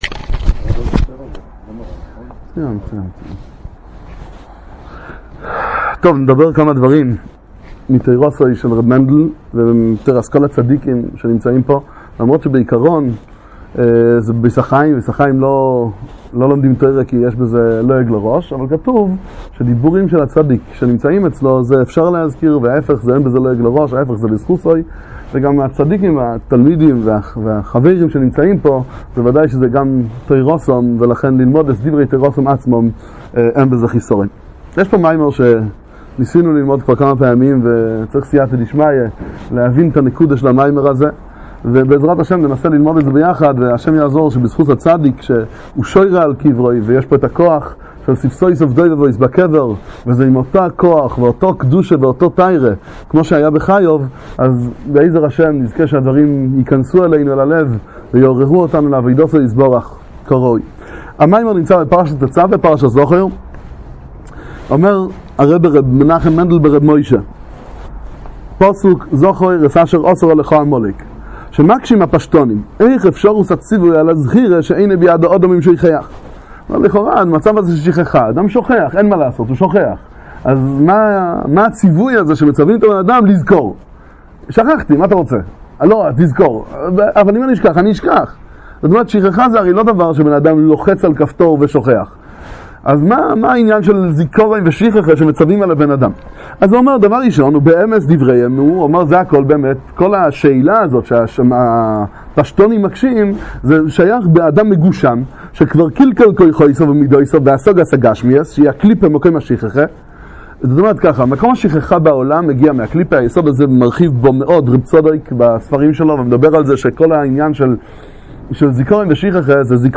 שיעורי תורה בספרי חסידות בפרשת השבוע ובחגי השנה